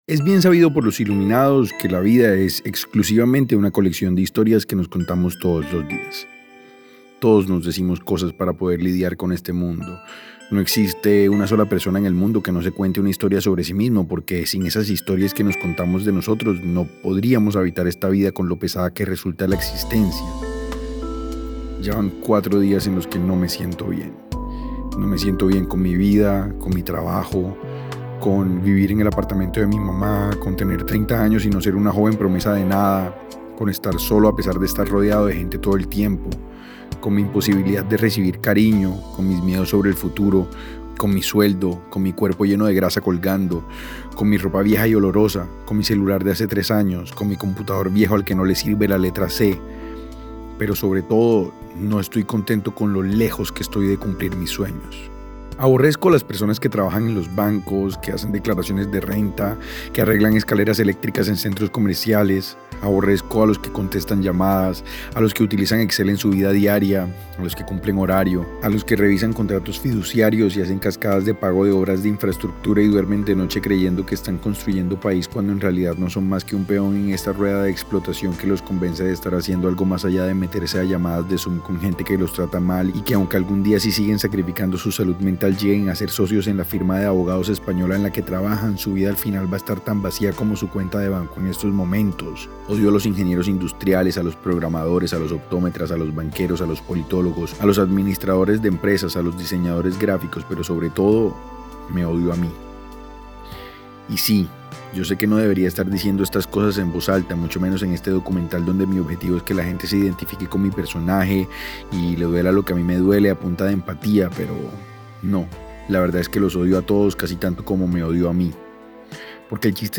En sus conversaciones, aceptan el presente y aprenden a soltar expectativas imposibles.